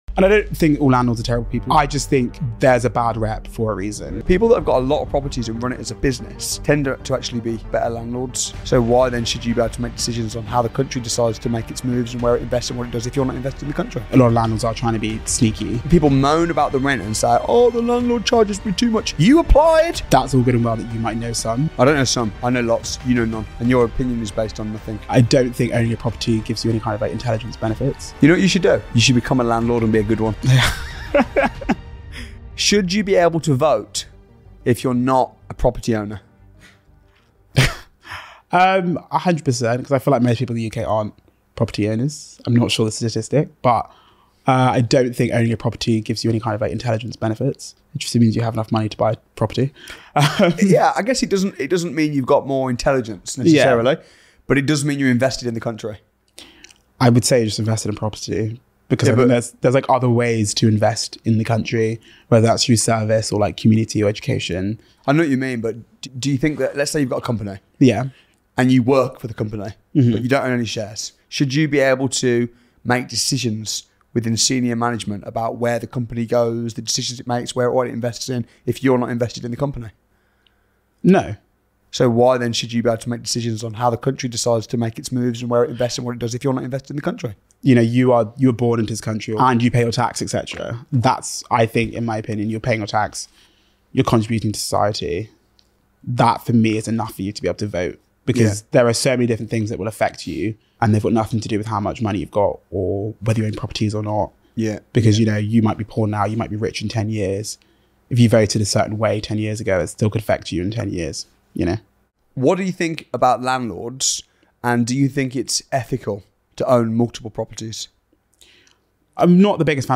Is Owning 100+ Properties Ethical? | Heated Debate on Landlords & Housing